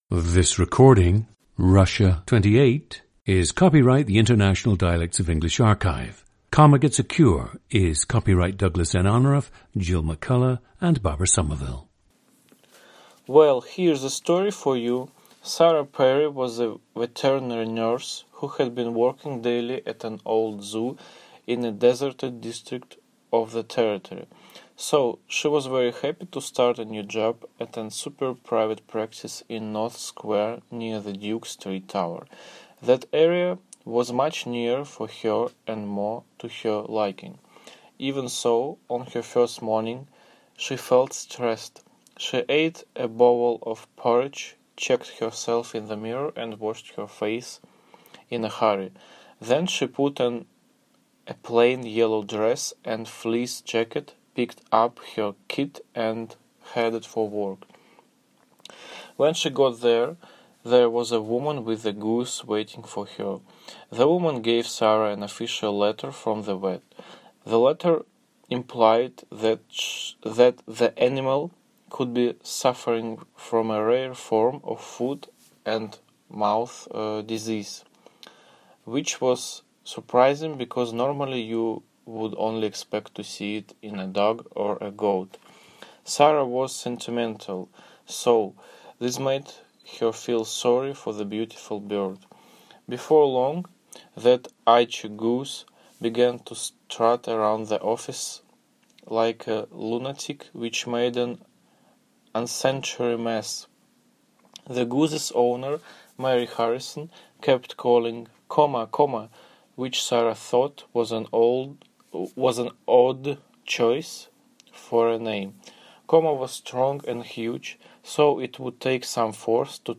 GENDER: male
The subject definitely gives an idea of the Russian accent, with vowels of the Russian language giving way to those of English. Stress in a couple of words is in the wrong place, and the nasal “n” sound is reduced at the ends of words.
• Recordings of accent/dialect speakers from the region you select.